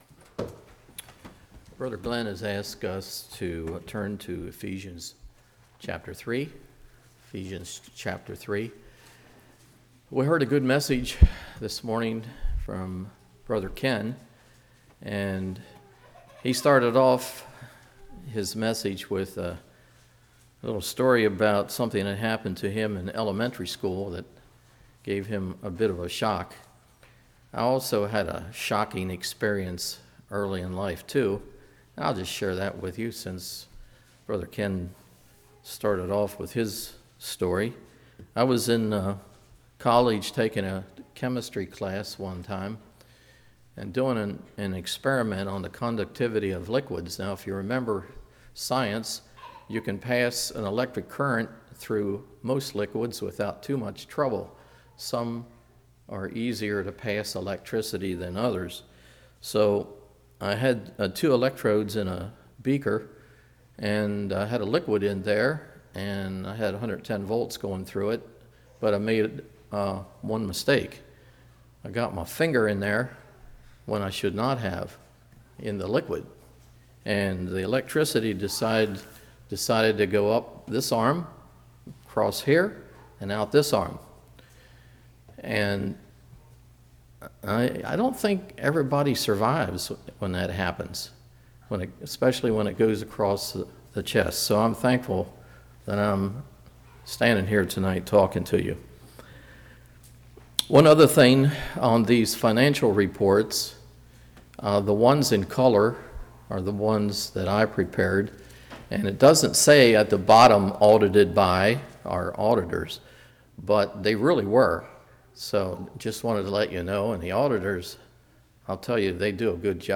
Ephesians 3:14-21 Service Type: Evening Who makes up family?